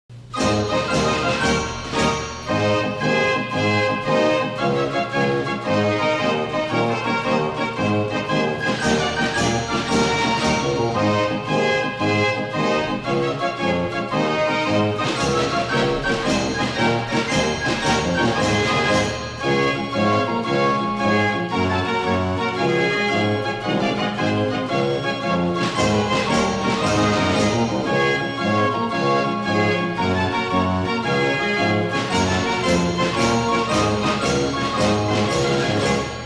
52 KEY FRENCH GASPARINI FAIR ORGAN